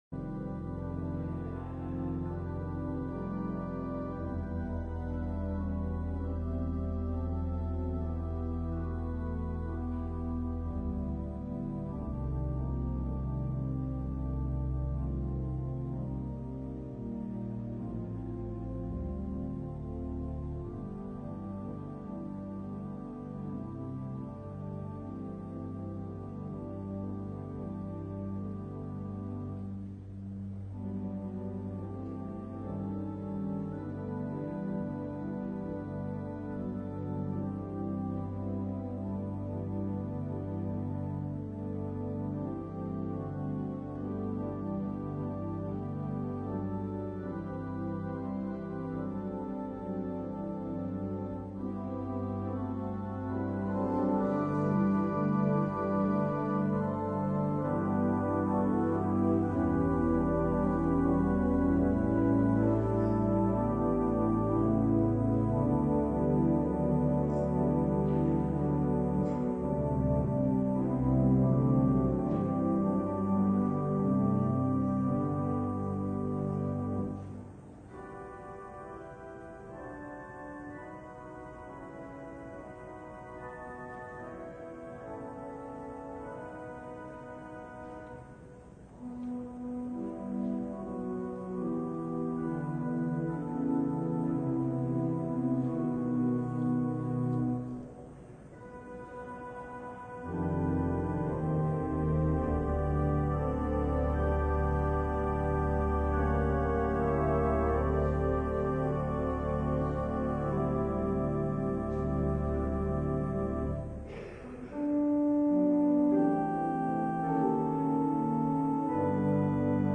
LIVE Evening Worship Service - Impassioned Call to Love